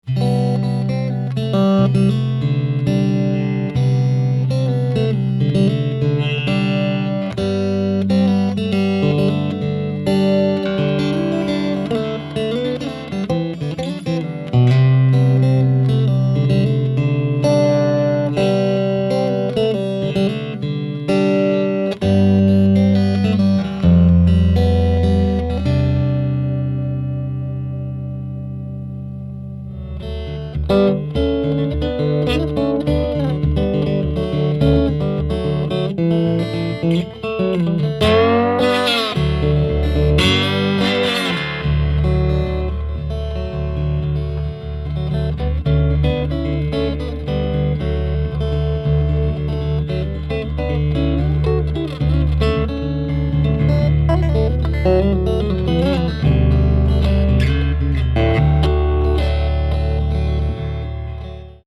Studio 210 with Blue Pups 76Strat through Kingsley Classic 30 and a little slide , Play Sample
210-KINGSLEY STRAT FADE IN.mp3